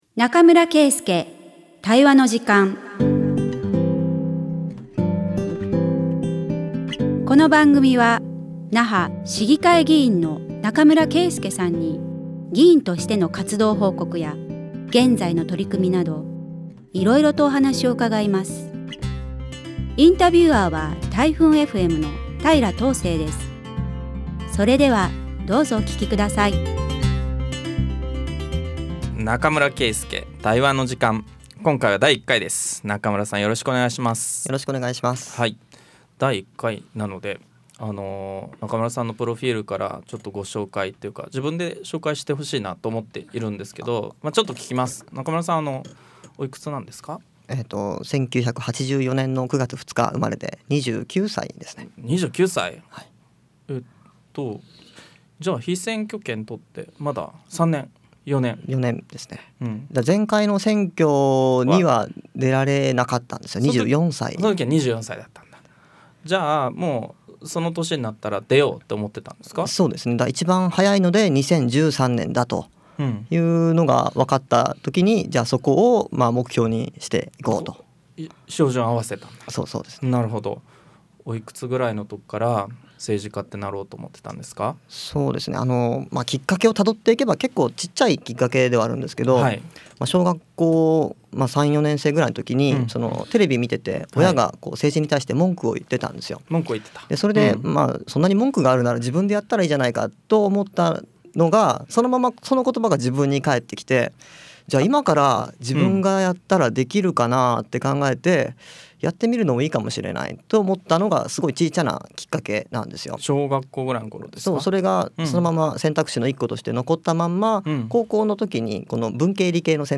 140321中村圭介対話の時間vol.03 那覇市議会議員中村圭介が議員活動や現在の取組みを語る20分
那覇市議会議員の中村圭介さんに、議員としての活動報告や、現在の取組みなどお話を伺います。